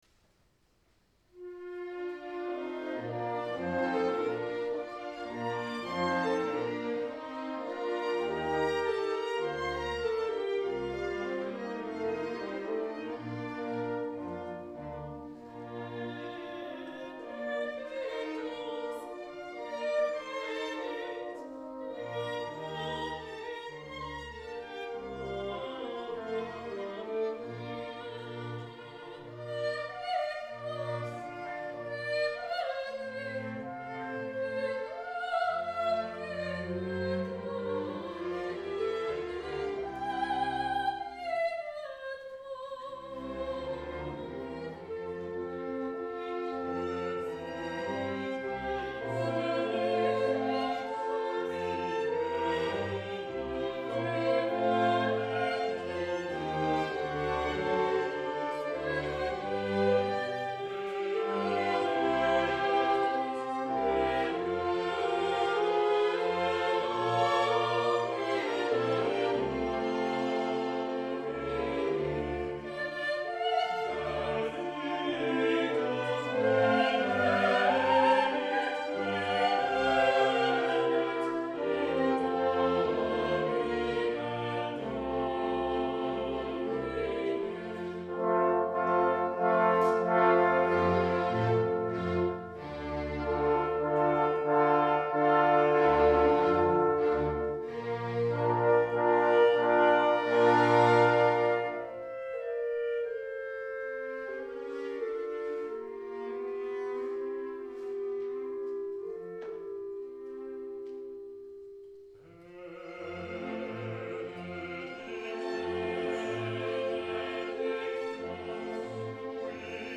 Konsertopptak